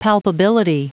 Prononciation du mot palpability en anglais (fichier audio)
palpability.wav